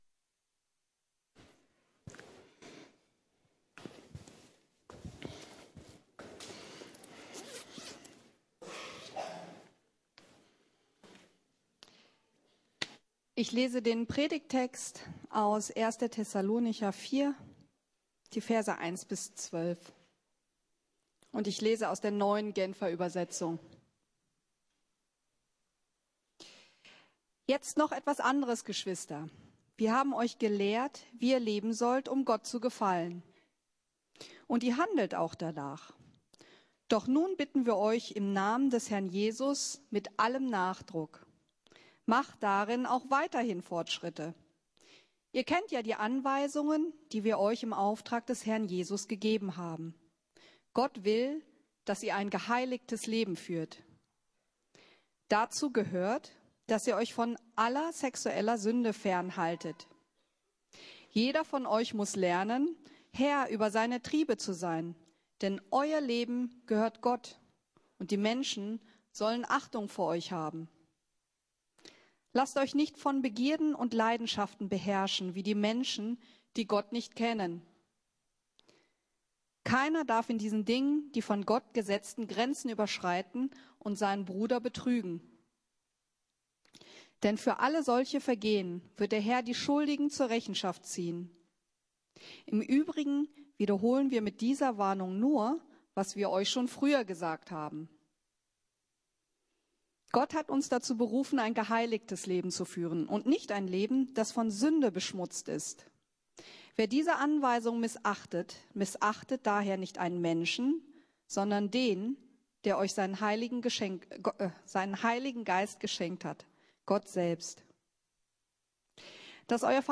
Perfekt! ~ Predigten der LUKAS GEMEINDE Podcast